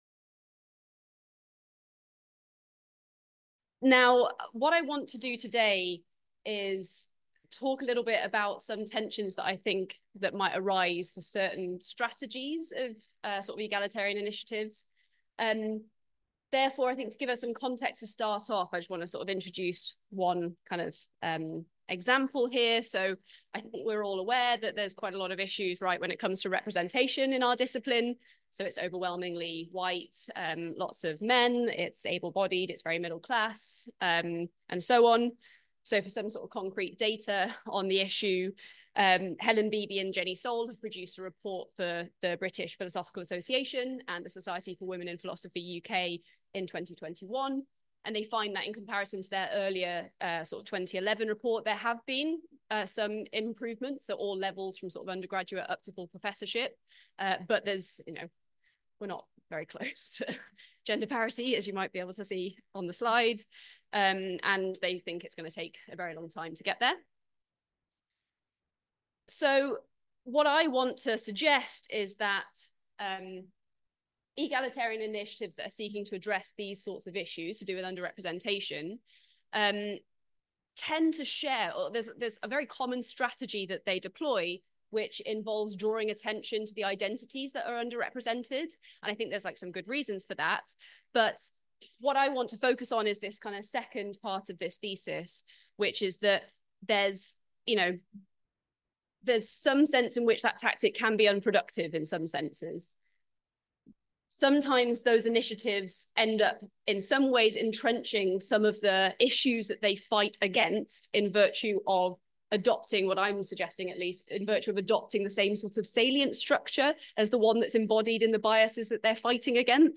Recordings of talks at the Faculty of Philosophy Moral Sciences Club.